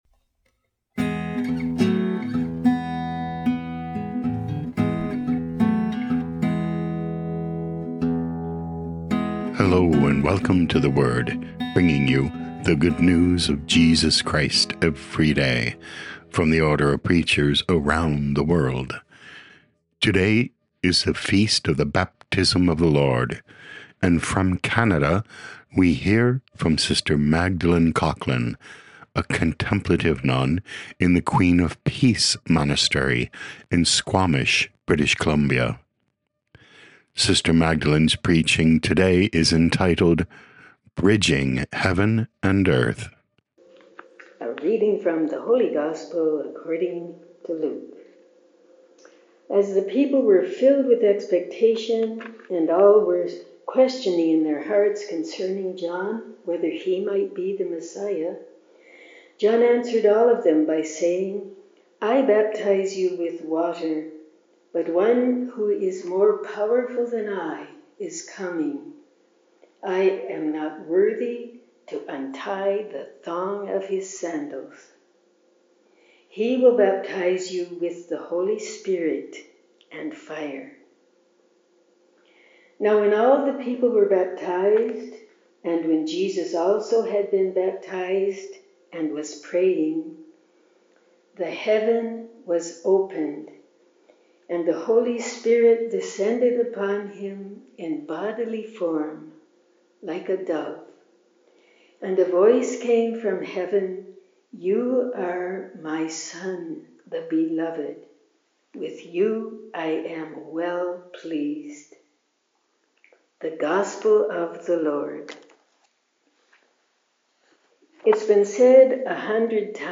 O.P. Preaching